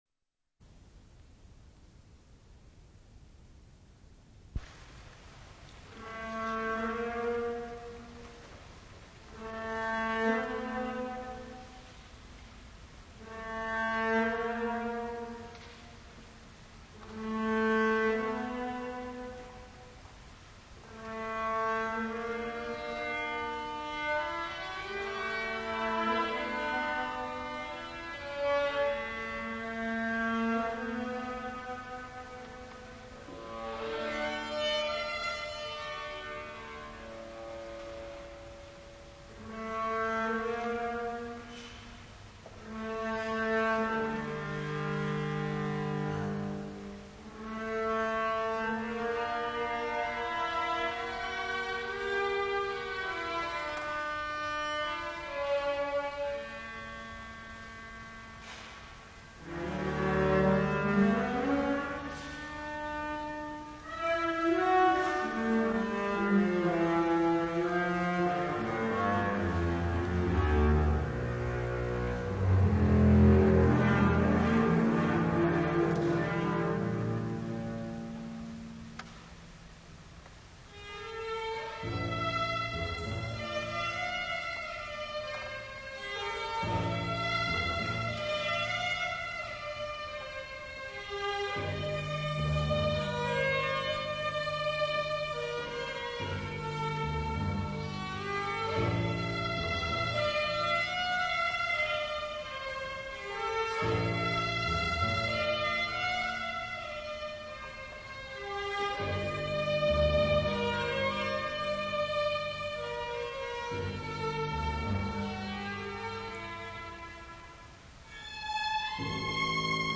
& Contrabass
Europaisches Music Festival
Stuttgart, Germany. (1988)